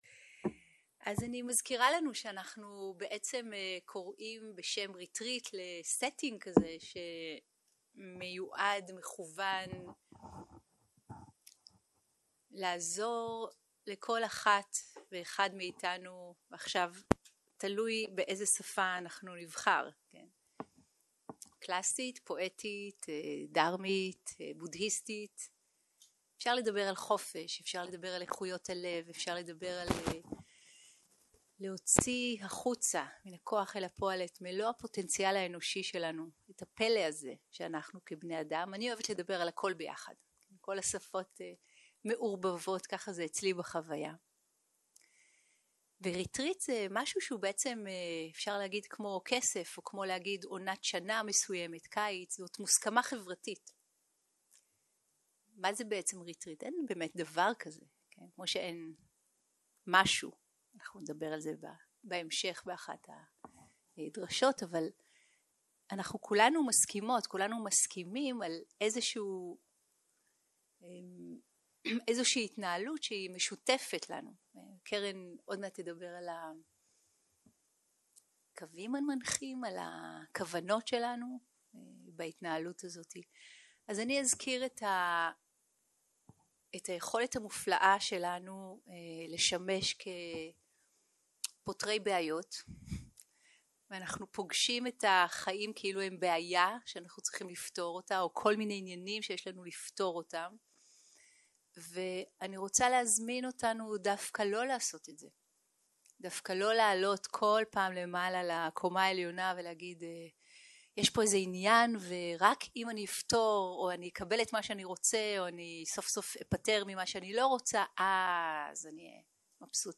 Dharma type: Opening talk